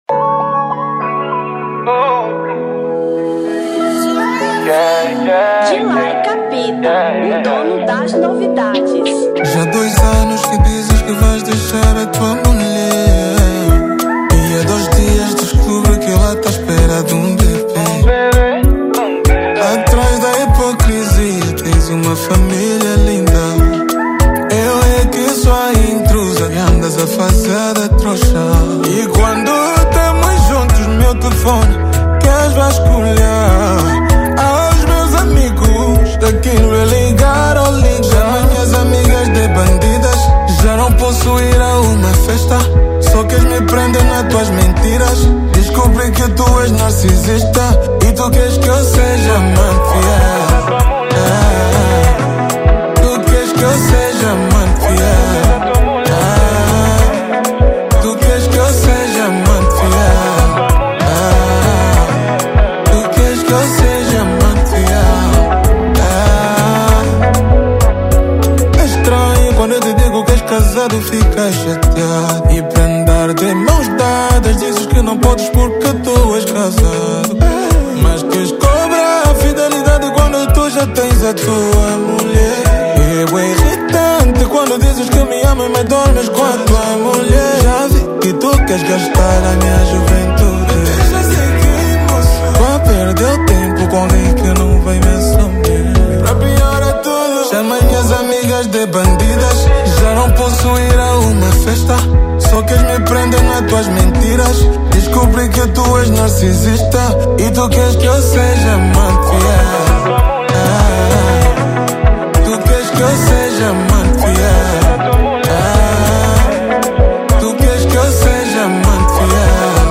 Kizomba 2024